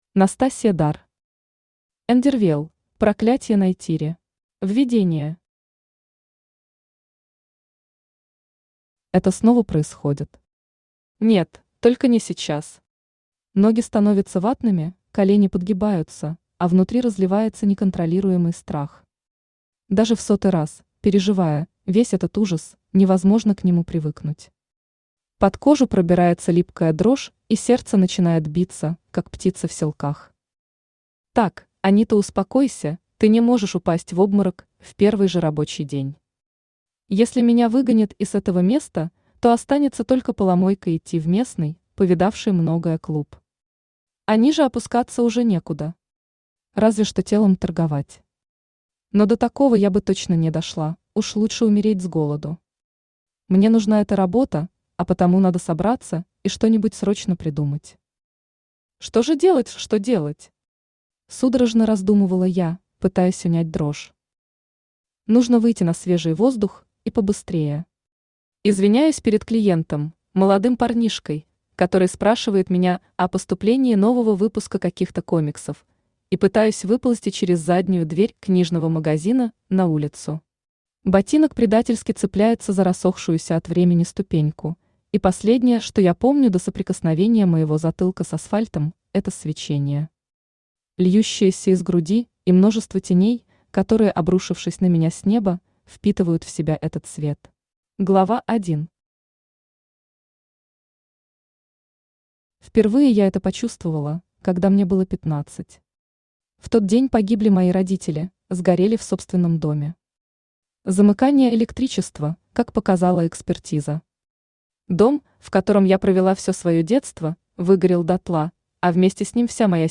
Аудиокнига Эндервелл: Проклятье Найтири | Библиотека аудиокниг
Aудиокнига Эндервелл: Проклятье Найтири Автор Настасья Дар Читает аудиокнигу Авточтец ЛитРес.